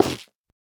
sounds / block / stem / break4.ogg